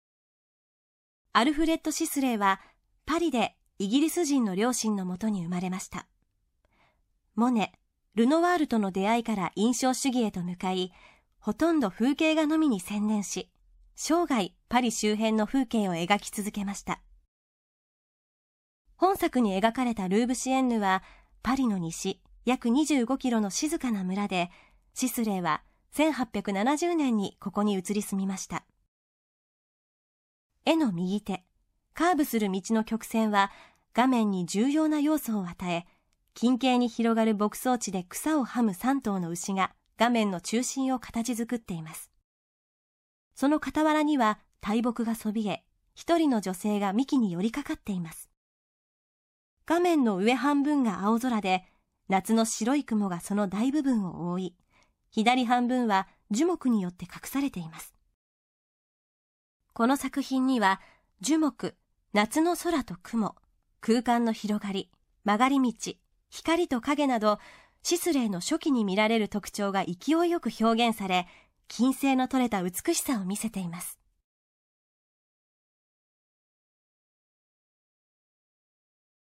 作品詳細の音声ガイドは、すべて東京富士美術館の公式ナビゲーターである、本名陽子さんに勤めていただいております。本名さんは声優、女優、歌手として幅広く活躍されています。